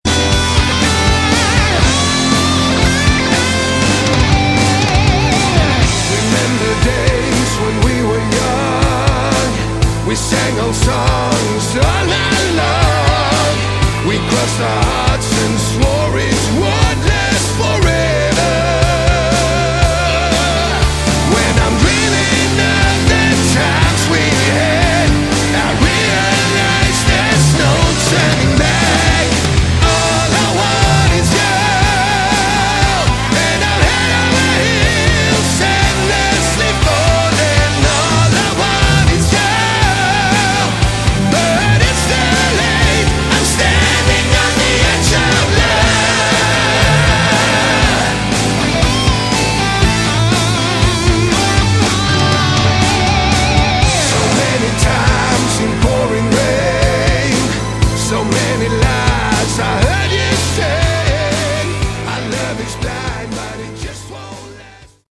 Category: Hard Rock
vocals